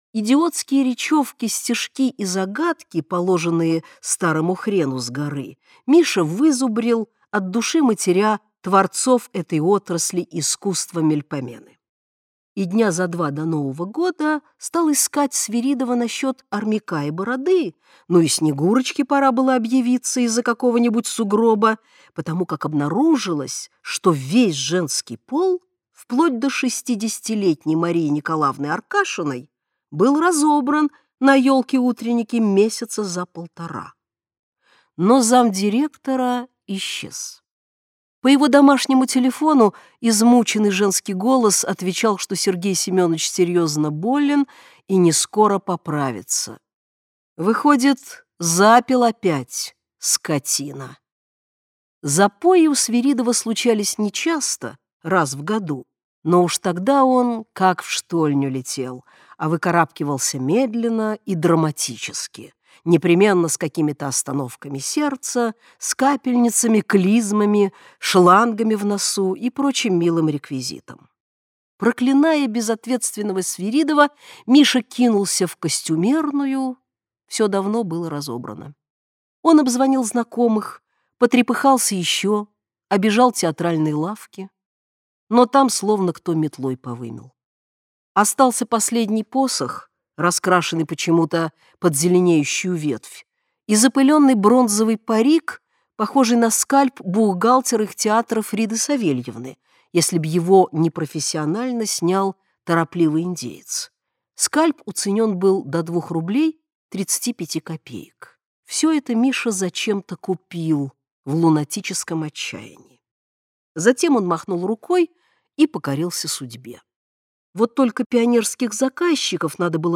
Аудиокнига Посох Деда Мороза | Библиотека аудиокниг
Aудиокнига Посох Деда Мороза Автор Дина Рубина Читает аудиокнигу Дина Рубина.